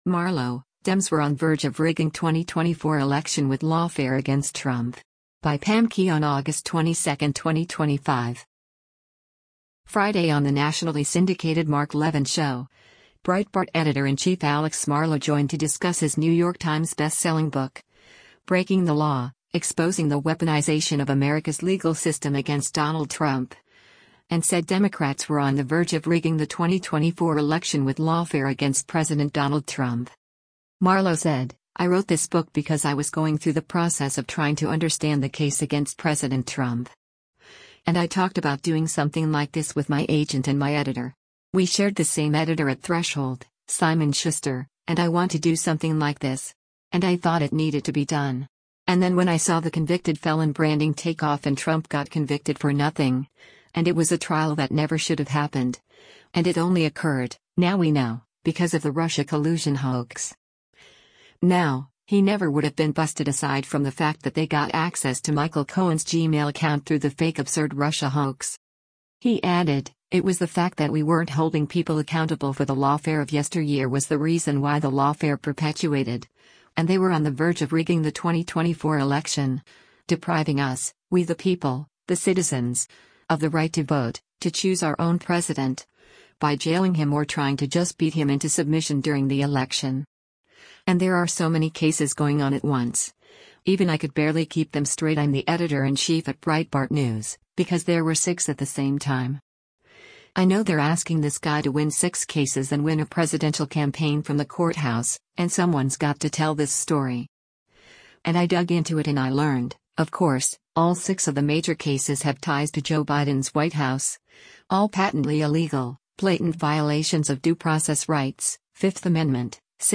Friday on the nationally syndicated “Mark Levin Show,” Breitbart Editor-in-Chief Alex Marlow joined to discuss his New York Times-bestselling book, Breaking the Law: Exposing the Weaponization of America’s Legal System Against Donald Trump, and said Democrats were on the verge of rigging the 2024 election with lawfare against President Donald Trump.